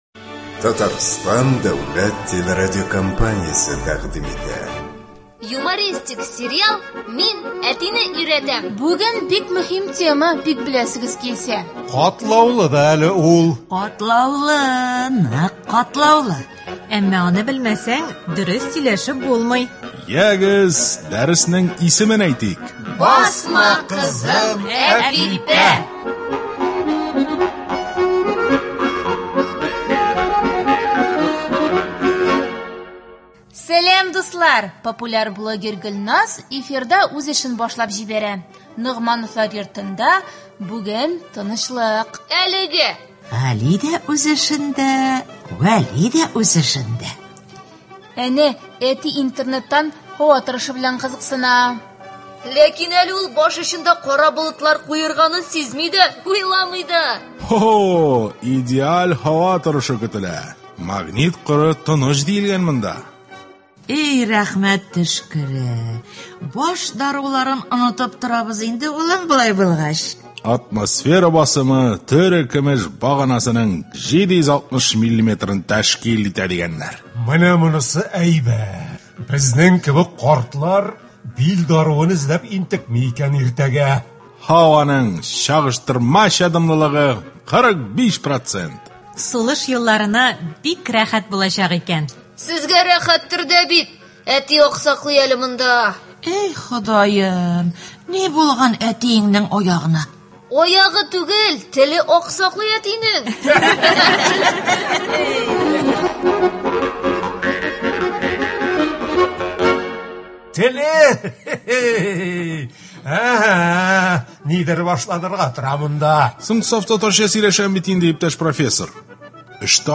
Игътибарыгызга радиосериалның чираттагы чыгарылышын тәкъдим итәбез. 21нче дәрес.